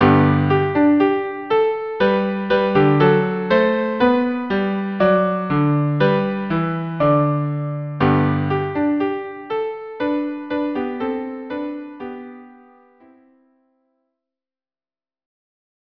traditional Hanukkah song
Instrumentation: Viola and Cello